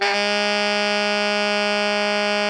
Index of /90_sSampleCDs/Giga Samples Collection/Sax/ALTO 3-WAY
ALTO GR G#2.wav